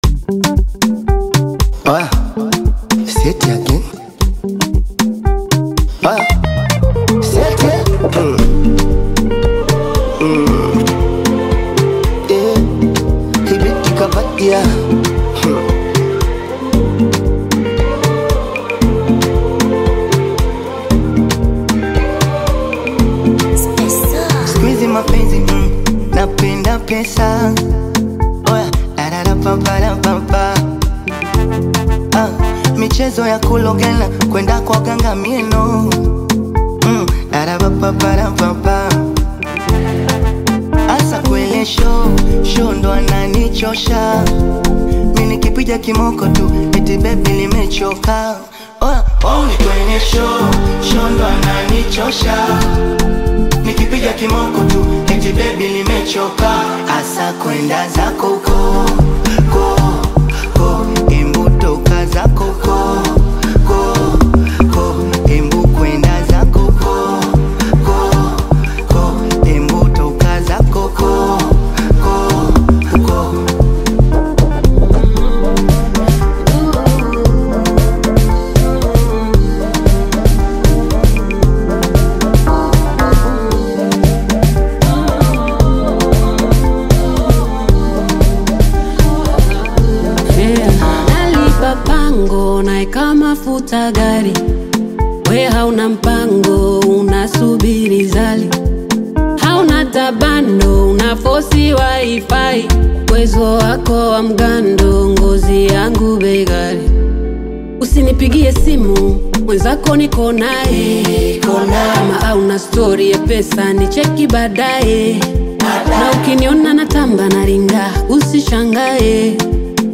is a vibrant Afro-Beat/Bongo Flava single
creating a catchy and danceable vibe.